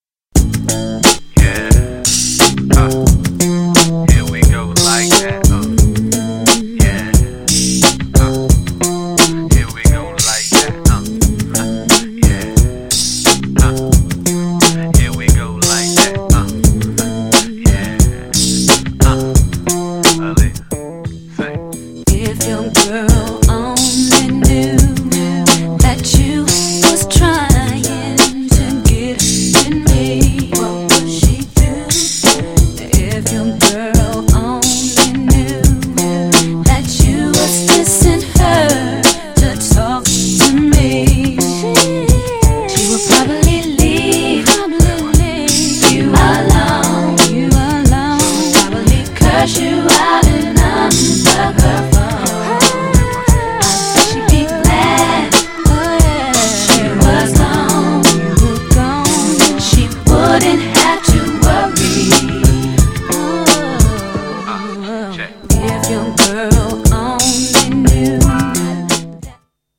彼女らしい憂いたっぷりのスロージャム!!
GENRE R&B
BPM 61〜65BPM
HIPHOPテイスト
女性VOCAL_R&B # 渋い系R&B